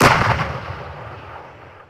44khz IMA.
t-80 firing distant.wav
t_80_firing_distant_141.wav